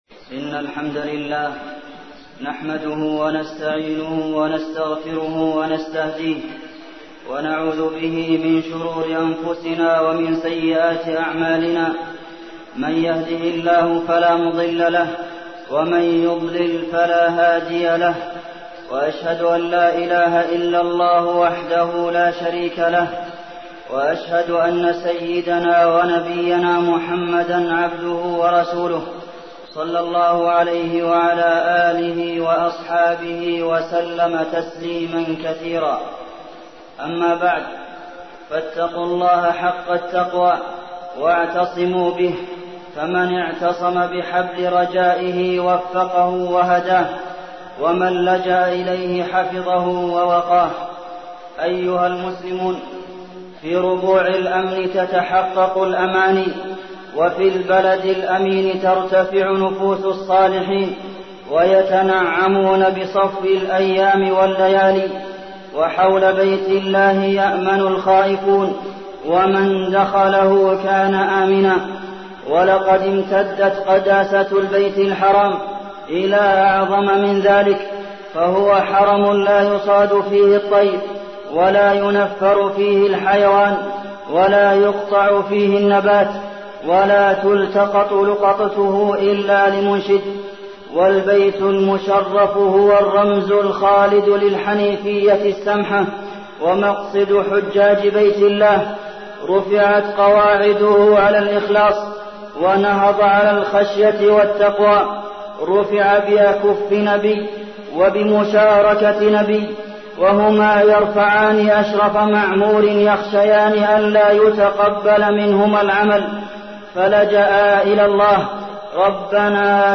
تاريخ النشر ٩ ذو الحجة ١٤١٩ هـ المكان: المسجد النبوي الشيخ: فضيلة الشيخ د. عبدالمحسن بن محمد القاسم فضيلة الشيخ د. عبدالمحسن بن محمد القاسم يوم عرفة